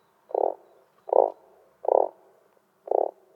animalworld_frog.ogg